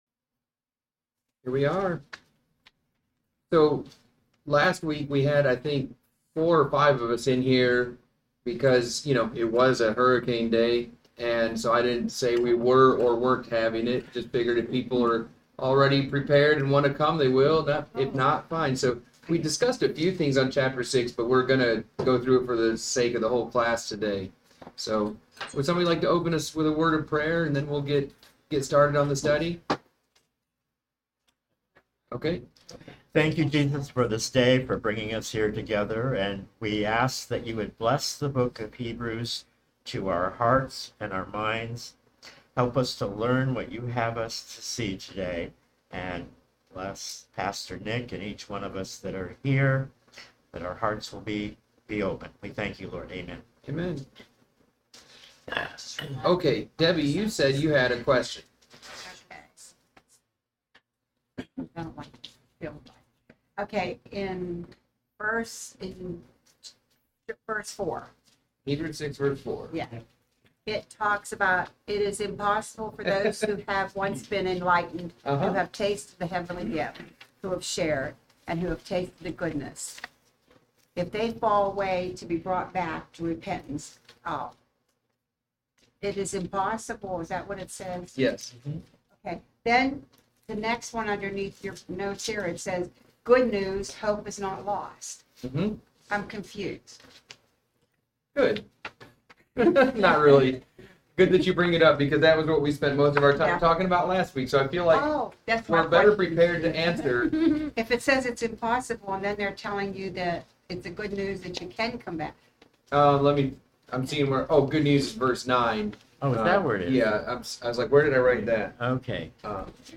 Pastor's Bible Study class on Hebrews 6